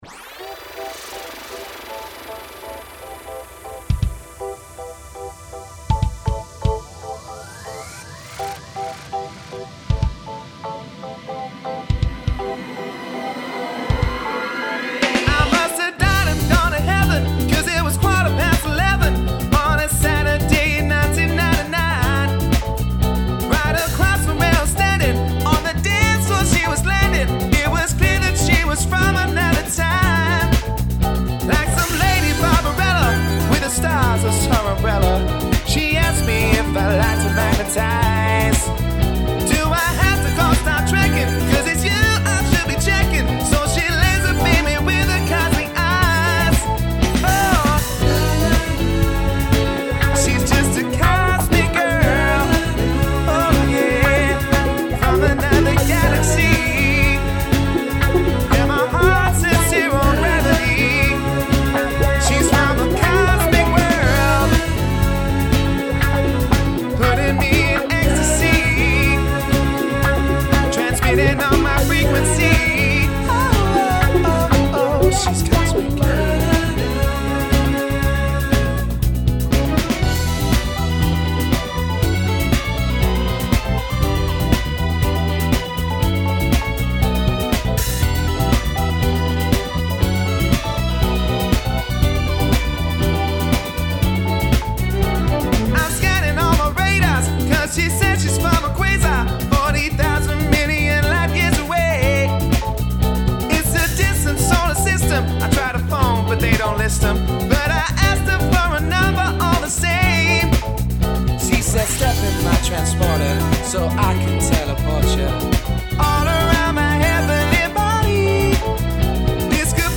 • Performing funk, soul, hip hop and garage music